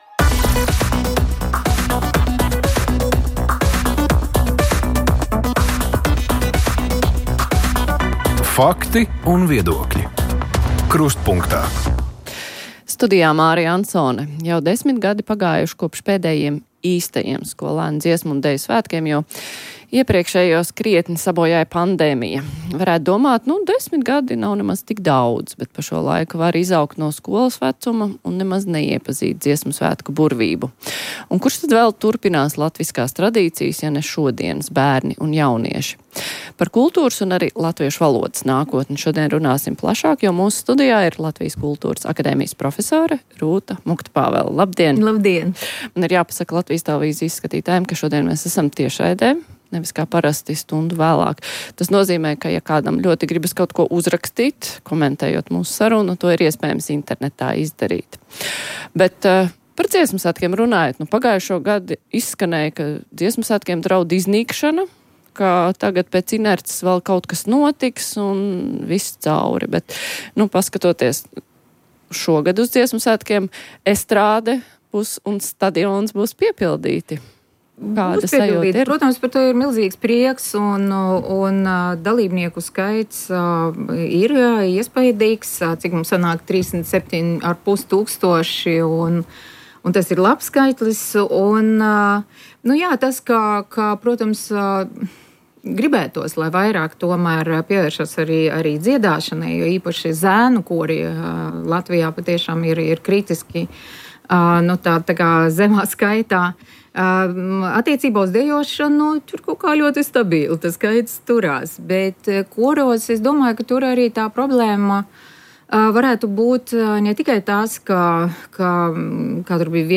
Tikai dažām politiskajā partijām biedru skaits pārsniedz tūkstoti. Kādēļ sabiedrības iesaiste partijās ir tik kūtra, par to diskusija Krustpunktā.